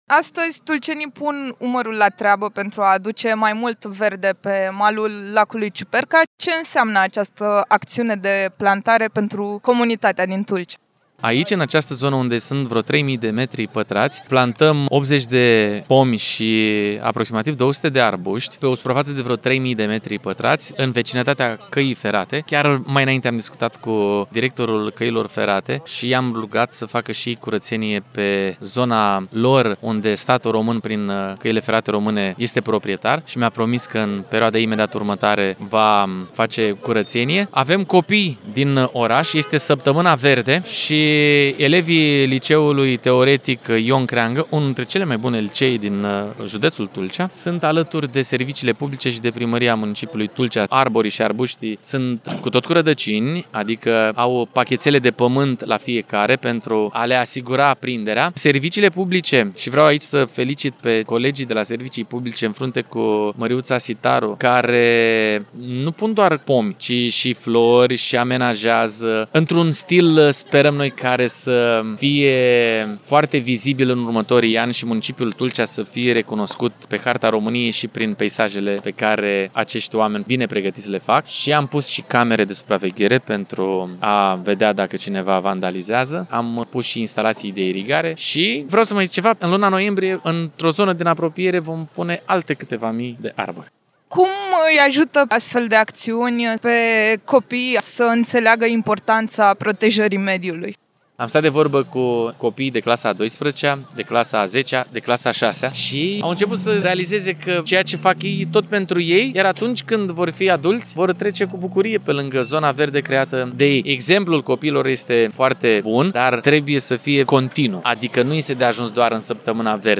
Printre participanți s-a aflat și primarul municipiului Tulcea, Ștefan Ilie, care spune că astfel de acțiuni îi învață pe tineri ce înseamnă respectul față de natură.